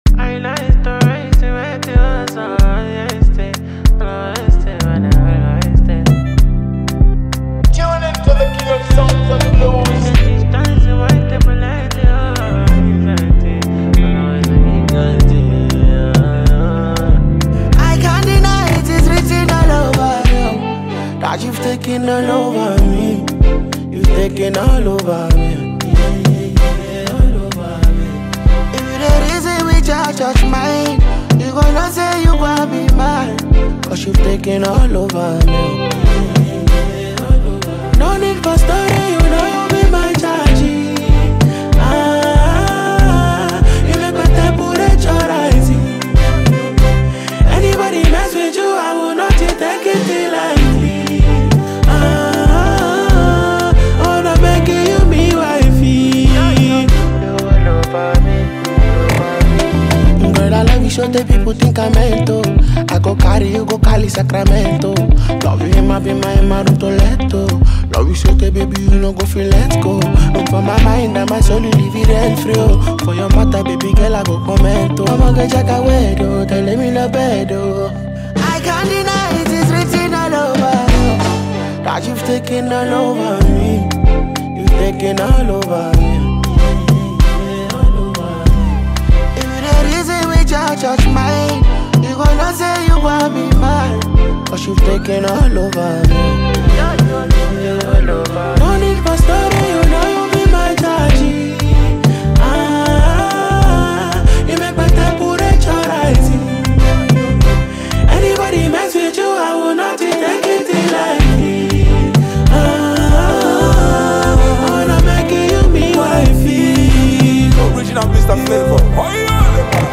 The song has a combination of harmonic melodies.